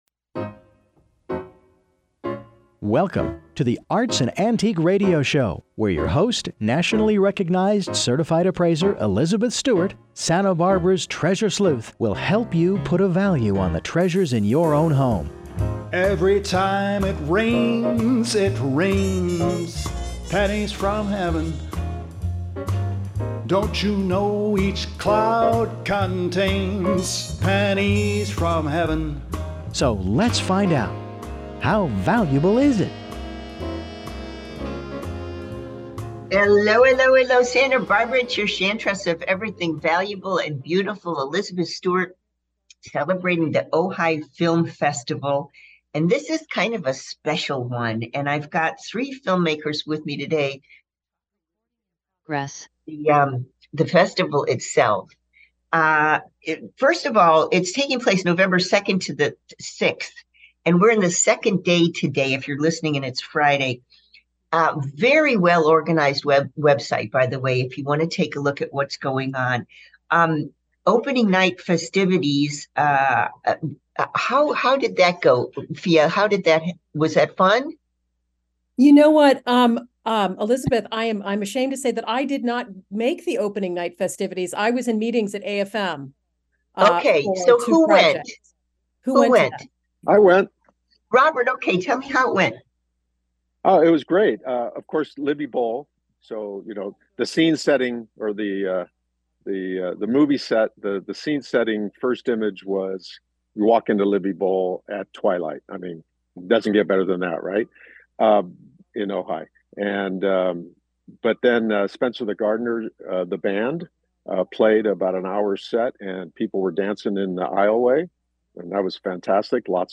Three filmmakers